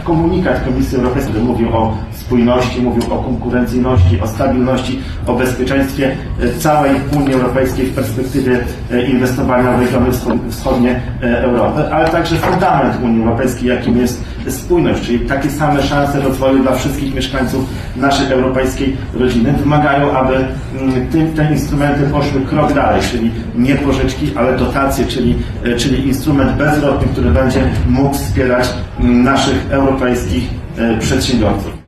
To główne postulaty marszałka województwa podlaskiego Łukasza Prokoryma podczas specjalnej konferencji zorganizowanej w Brukseli, a dotyczącej wsparcia regionów granicznych Unii Europejskiej. – Mamy już specjalny instrument finansowy o nazwie „East Invest”. Jednak w obecnej chwili dla regionów z Polski, Litwy, Łotwy, Estonii i Finlandii potrzeba czegoś więcej – mówił w Brukseli marszałek województwa podlaskiego Łukasz Prokorym.